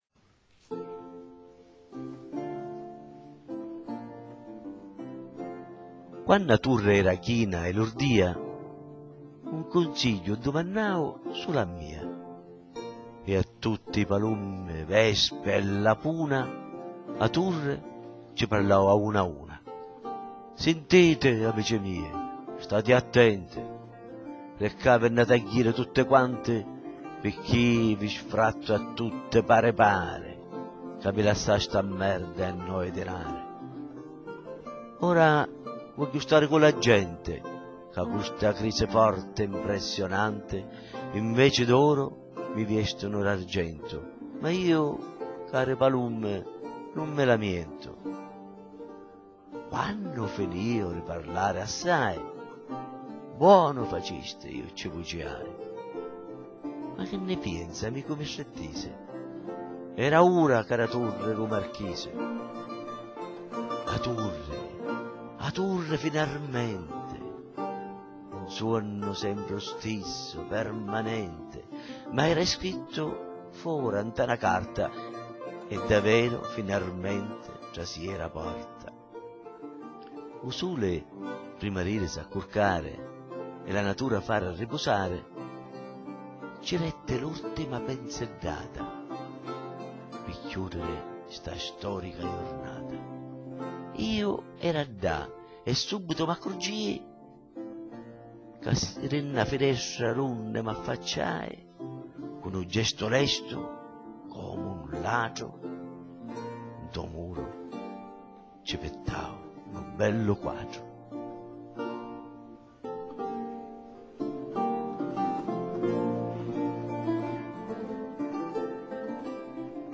Recitazione
La turri, finarmenti - Voce e musica.mp3